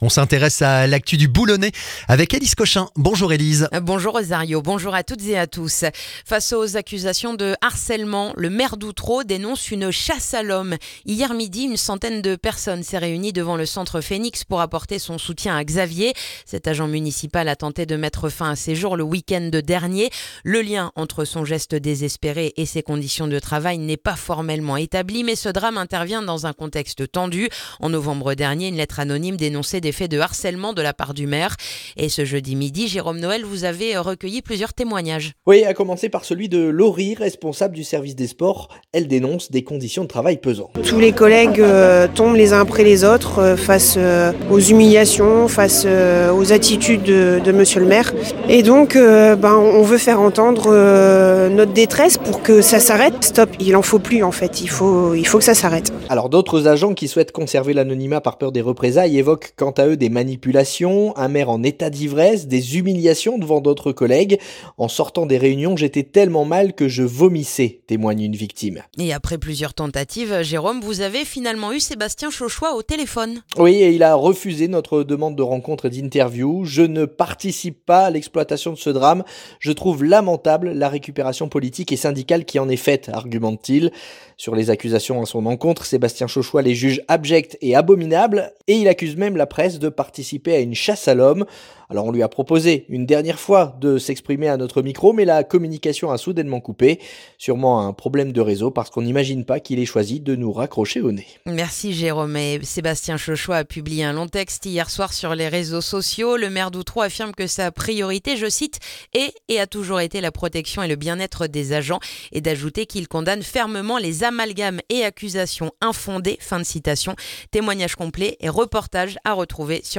Le journal du vendredi 13 février dans le boulonnais